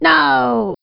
voice_no.wav